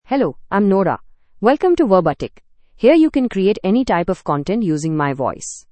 FemaleEnglish (India)
NoraFemale English AI voice
Nora is a female AI voice for English (India).
Voice sample
Listen to Nora's female English voice.
Nora delivers clear pronunciation with authentic India English intonation, making your content sound professionally produced.